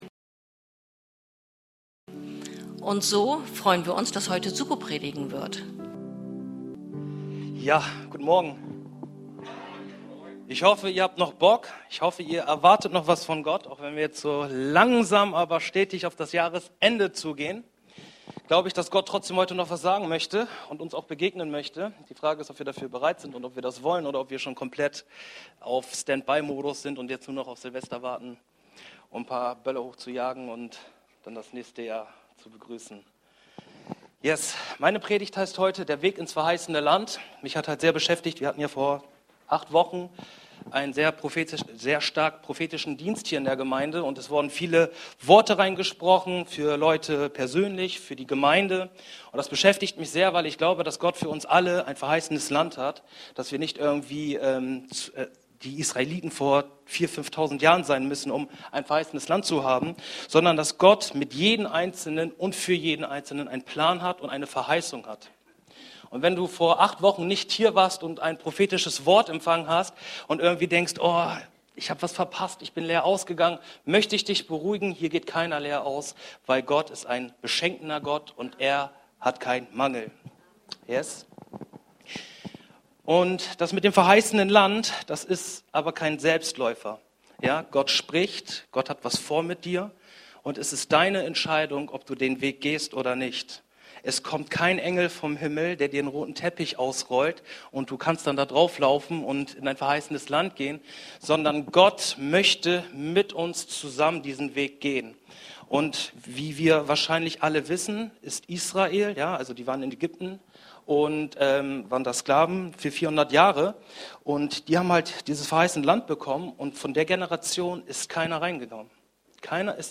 Predigten – OASIS Kirche